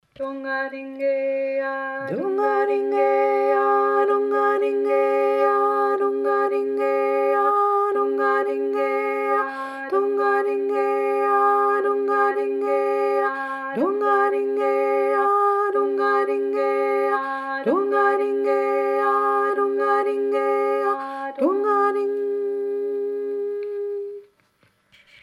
und nun alleine, ich singe die untere Note und die obere und ihr singt jene in der Mitte, die sich eben ändern - einfach probiern!